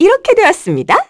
Juno-Vox_Skill4-2_kr.wav